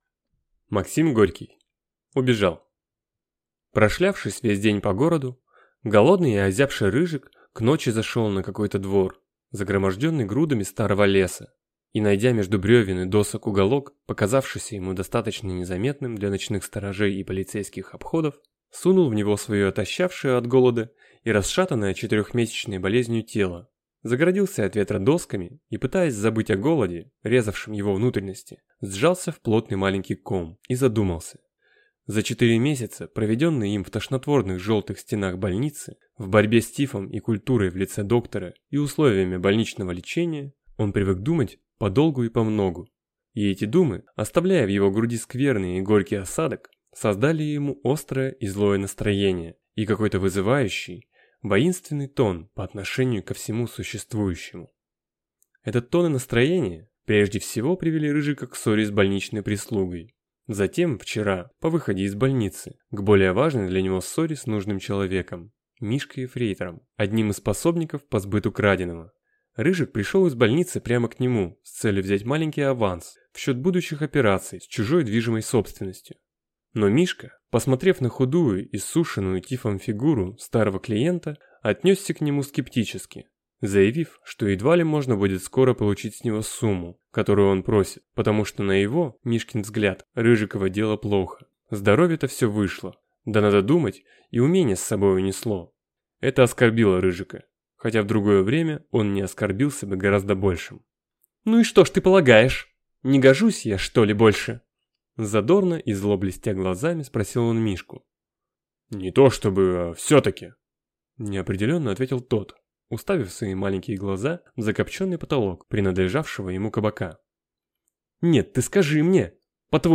Аудиокнига Убежал | Библиотека аудиокниг